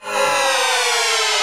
beesdie.wav